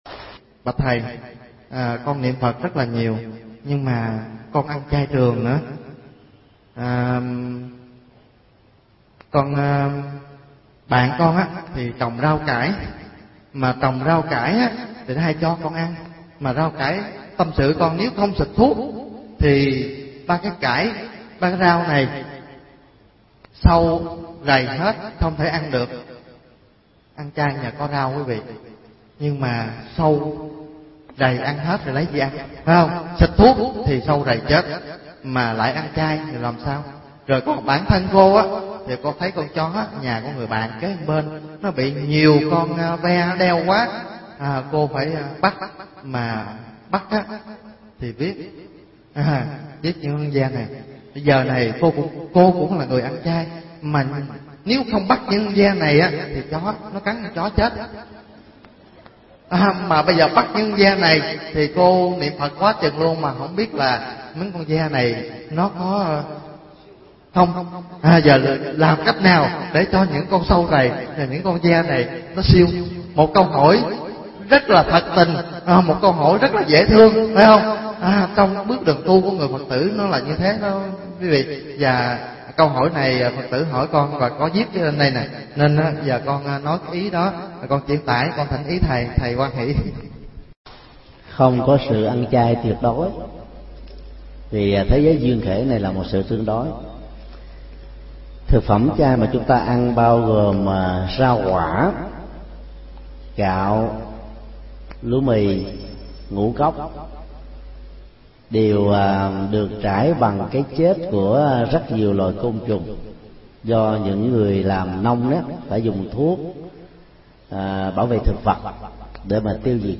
Mp3 Vấn đáp: Hiểu sao cho đúng về ăn chay tương đối, nghiệp sát côn trùng